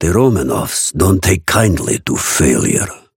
Raven voice line - The Romanovs don't take kindly to failure.